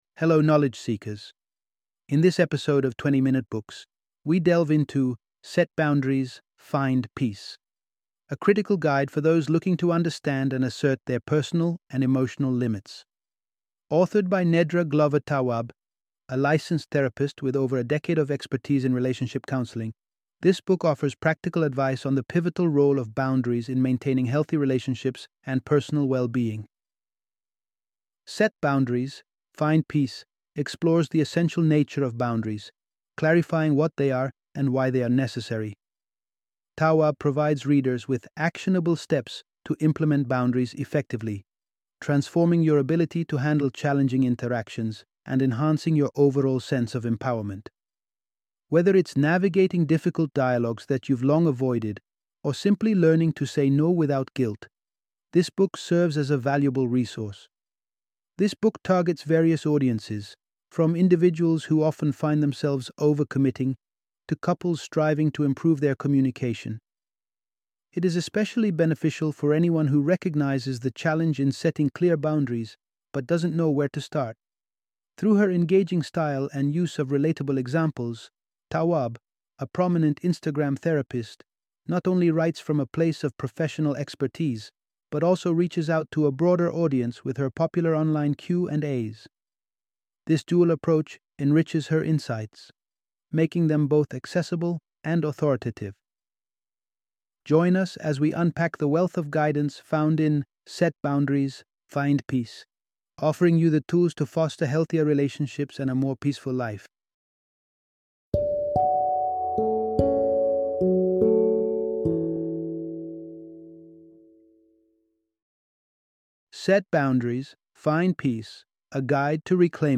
Set Boundaries, Find Peace - Audiobook Summary